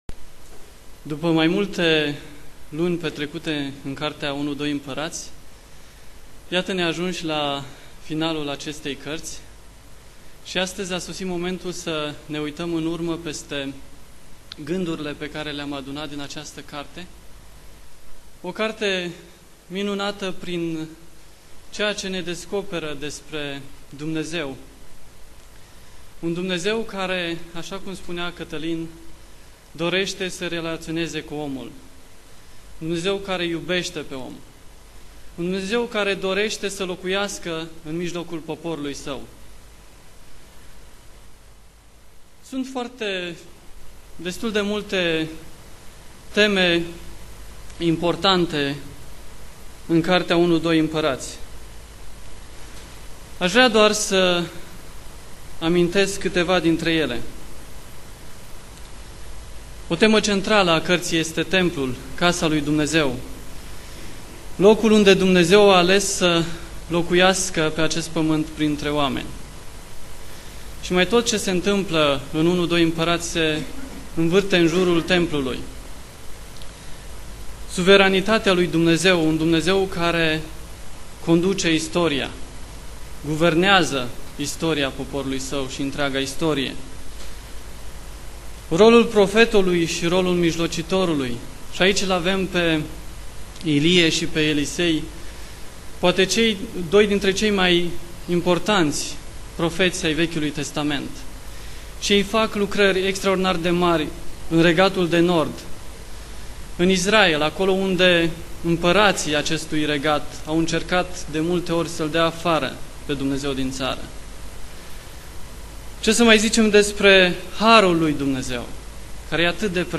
Predica Exegeza 2 Imparati Recapitulare